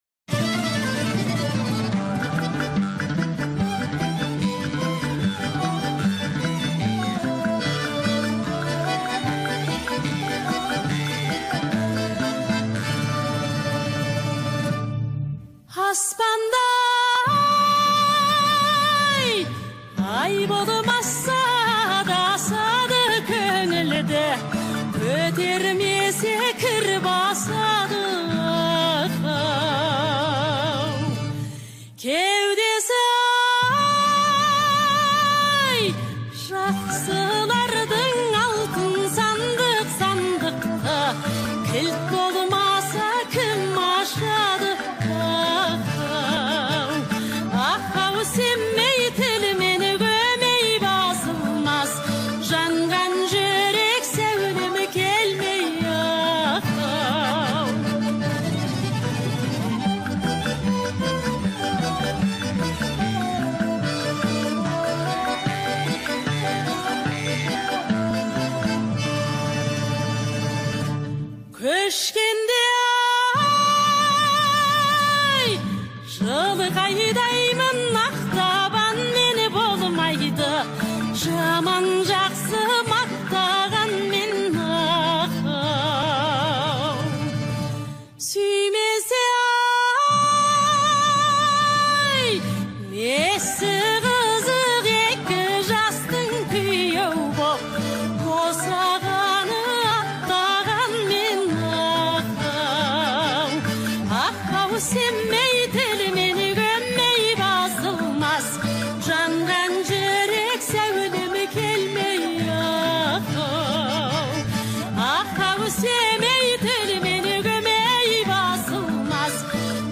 1.Халық әні